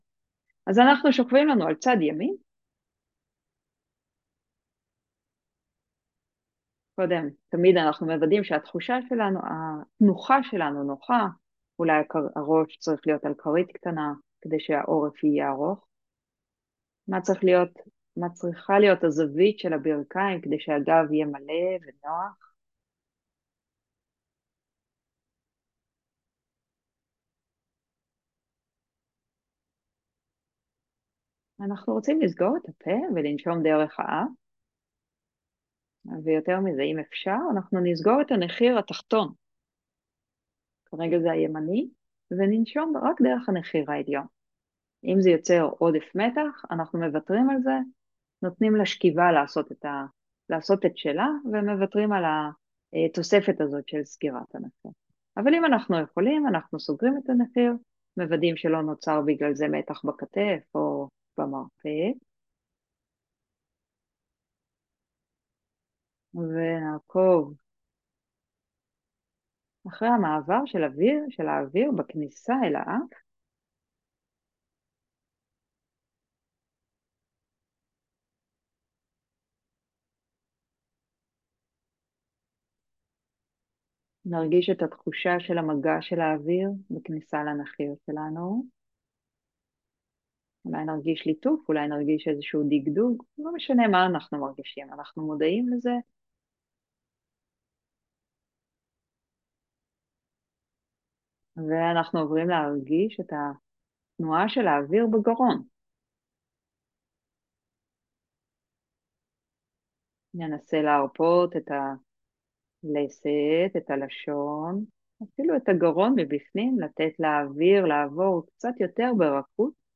תרגול נשימה ראשון
תרגול-נשימה-ראשון.m4a